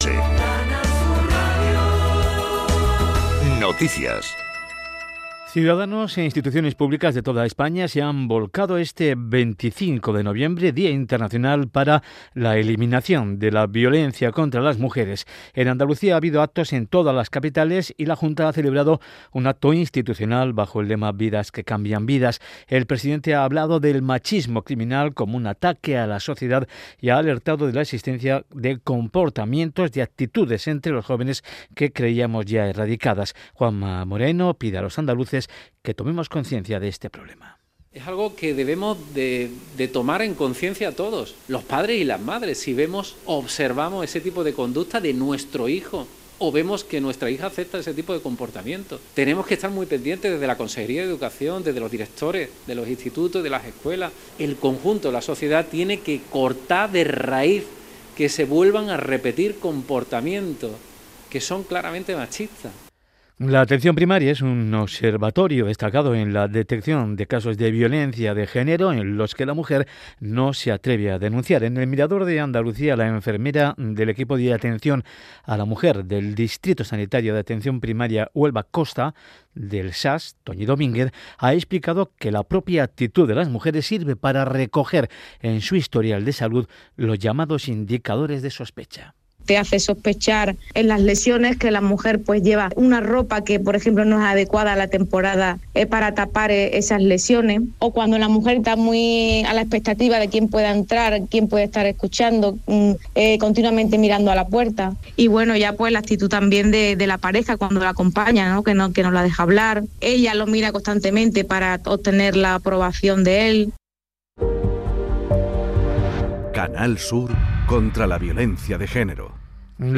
Canal Sur Radio y Radio Andalucía Información son las únicas cadenas que transmiten para toda Andalucía y el mundo el Concurso de Agrupaciones del Carnaval de Cádiz de manera íntegra.